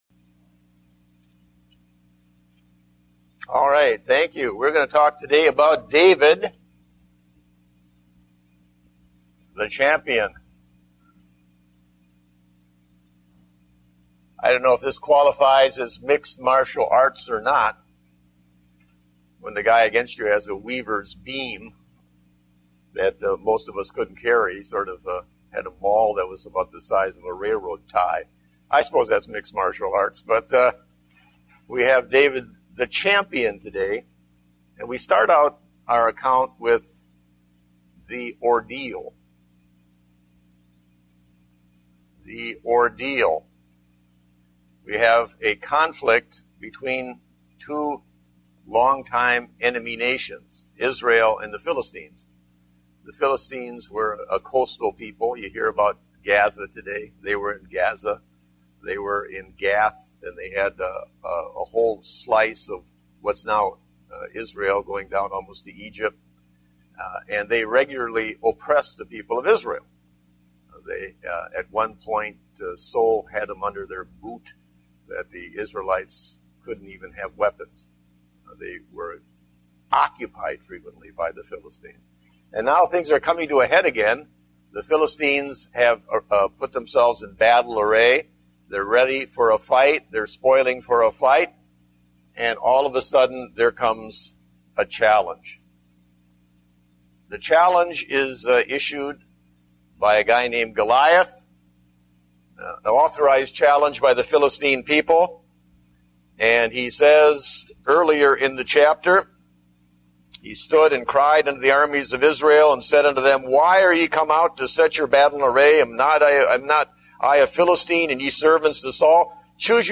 Date: October 4, 2009 (Adult Sunday School)